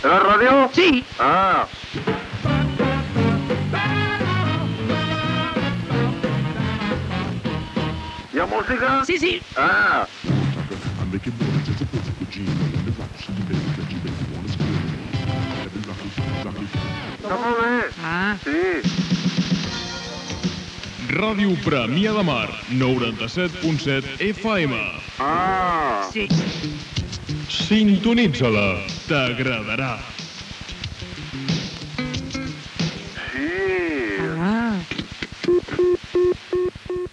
Indicatiu de l'emissora